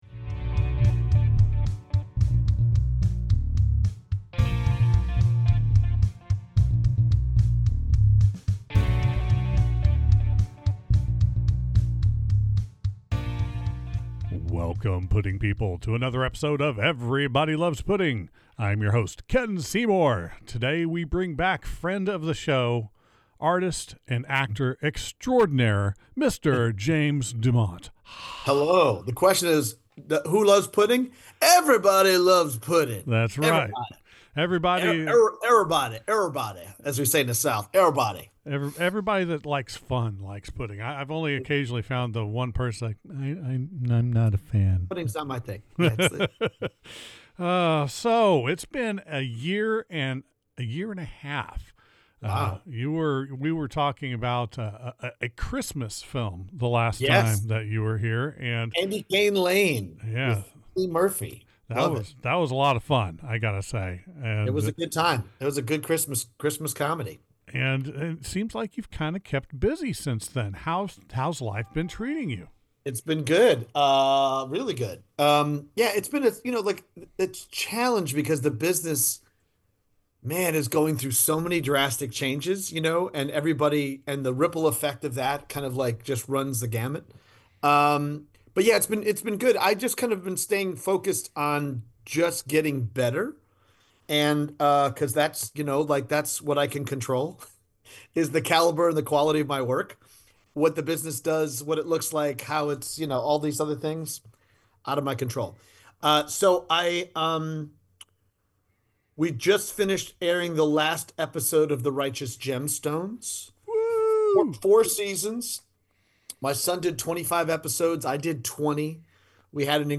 7.42: Interview